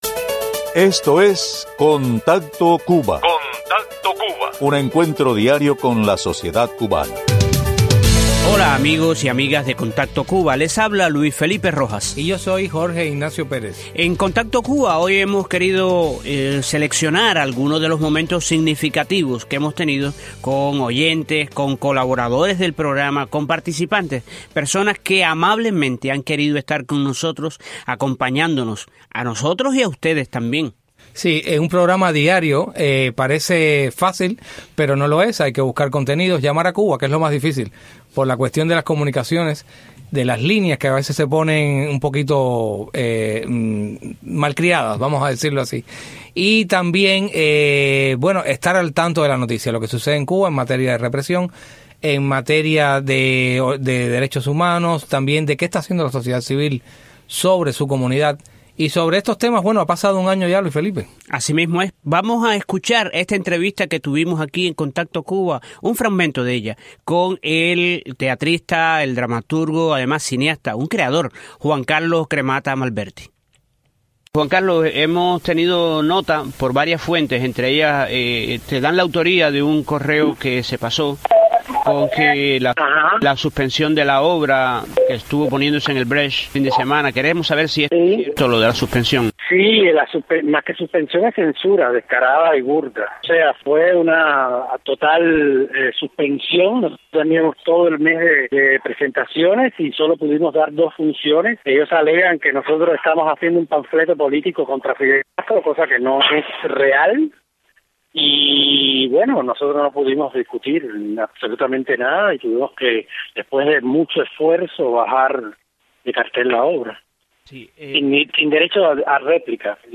Se pone a consideración de los oyentes una selección de entrevistas a quienes libran día a día el camino hacia la democracia en la isla. Escúchela en las voces de artistas, activistas políticos, Damas de Blanco, cubanos del exilio y otros de visita en Miami.